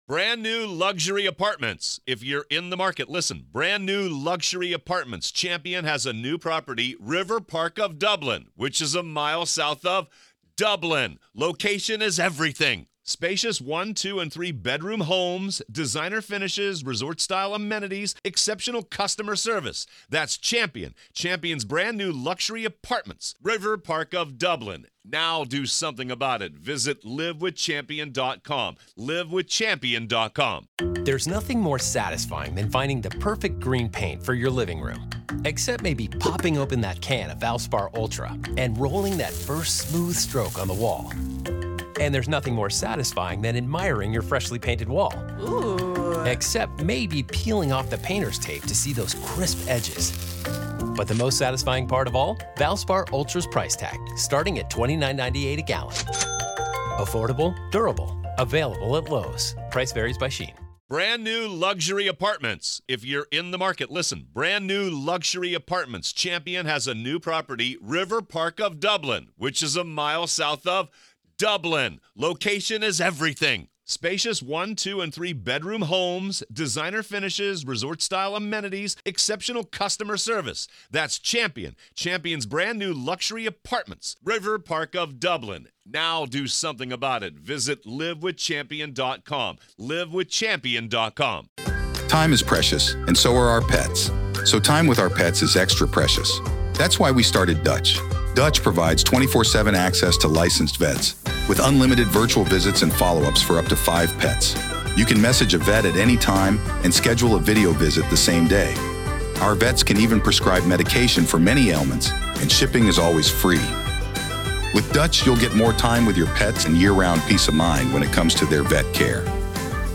Main Points of the Conversation